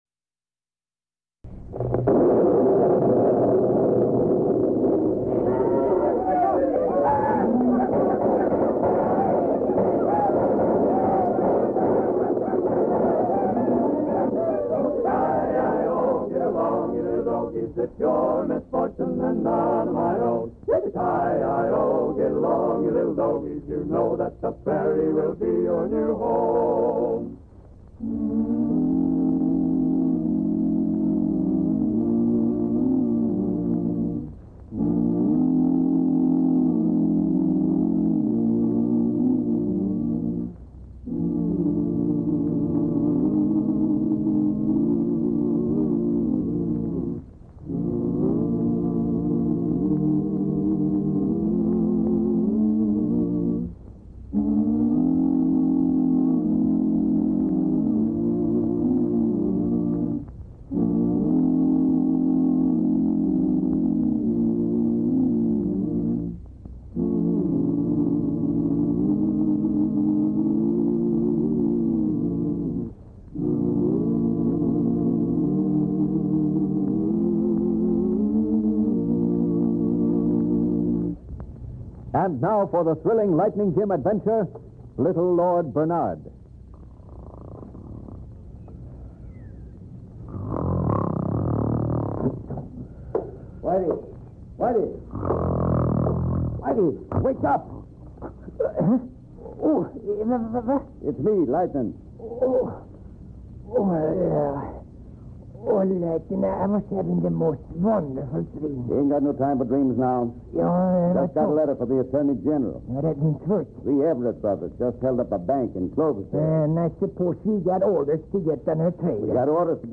"Lightning Jim" was a popular old-time radio show that featured the adventures of U.S. Marshal Lightning Jim Whipple. - The character of Lightning Jim, along with his trusty horse Thunder and deputy Whitey Larson, became iconic figures in Western radio drama.